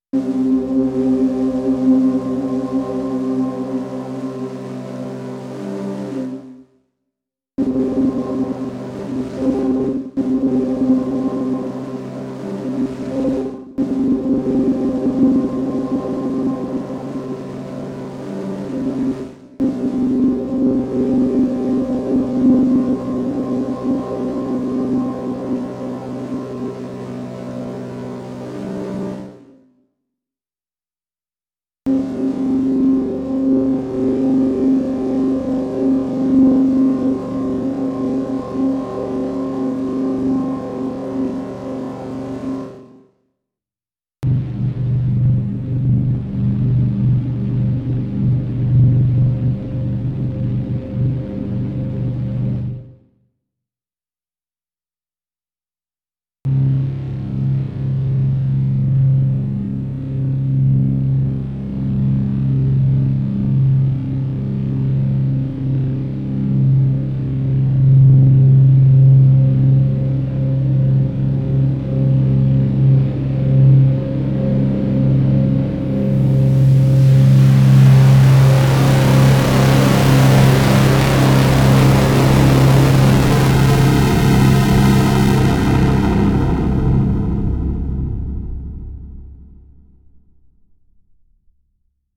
This is a pad sound put through the same process, with some pitching and mild effects at the end as well